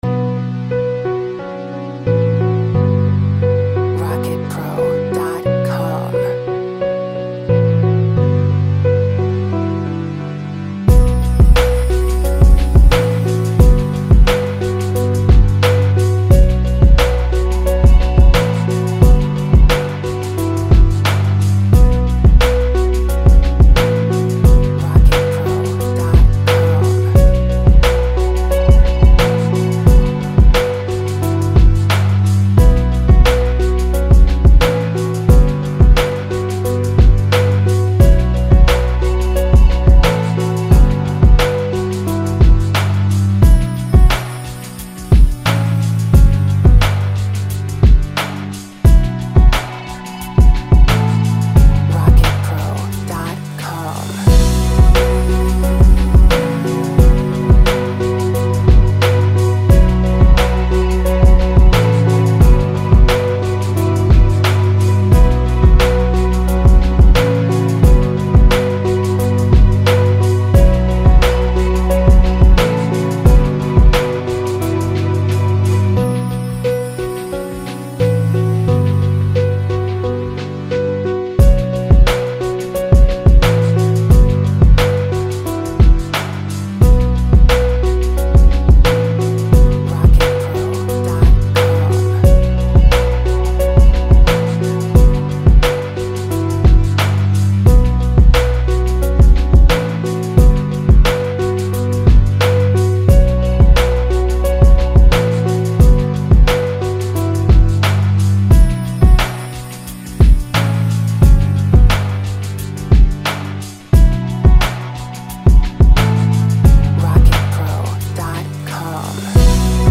Deep, sad beat with pianos, soft choirs, and violins.